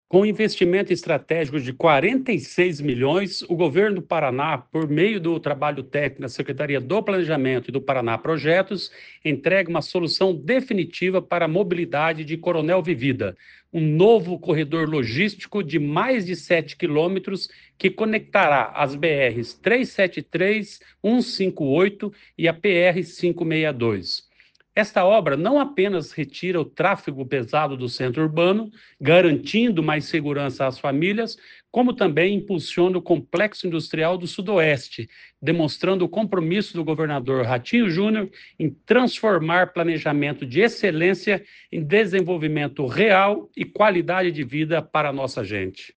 Sonora do secretário do Planejamento, Ulisses Maia, sobre o novo contorno viário de Coronel Vivida